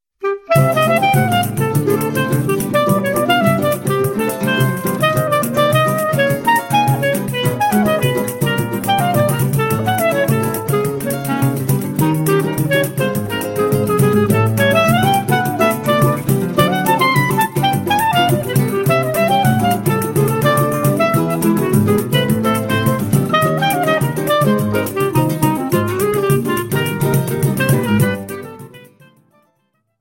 clarinet
Choro ensemble